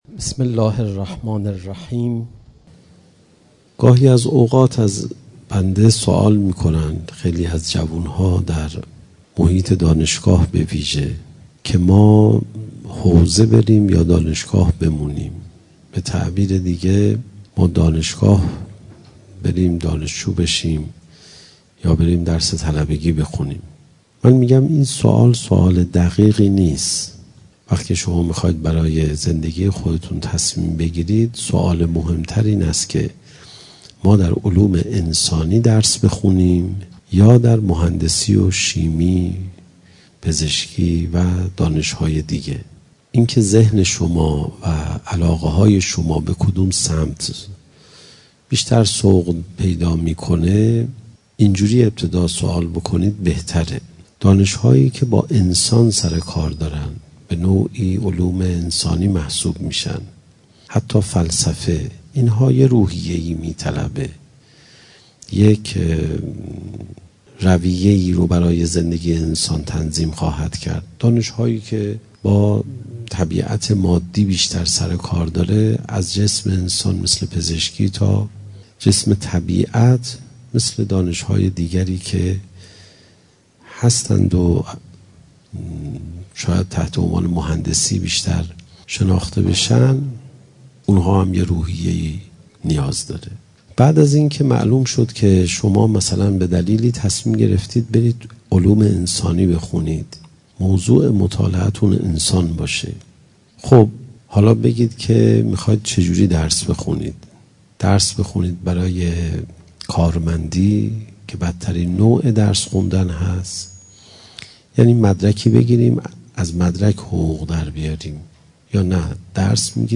مکان: دانشگاه علامه طباطبایی مناسبت: اعتکاف